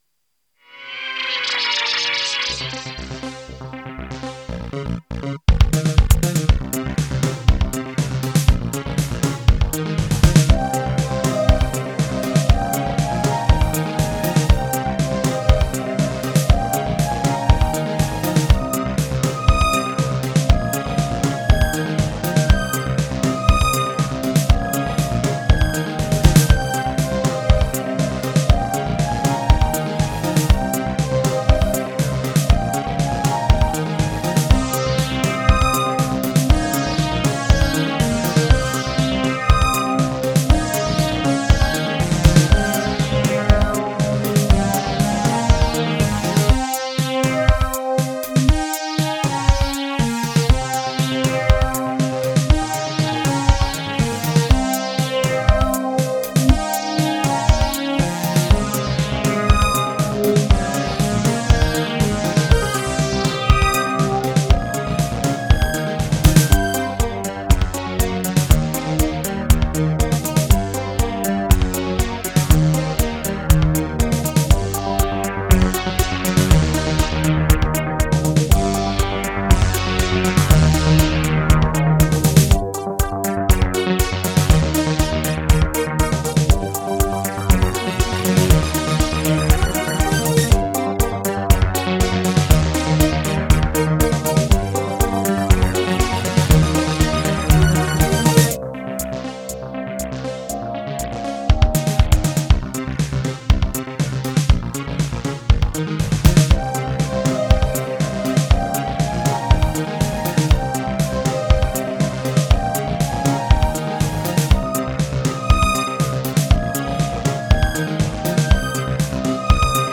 Single Mix